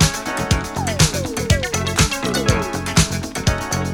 (that will add to my Disco House collection well).
Turns out it was a piano.
Anyway, have a listen, I think it’s pretty groovey…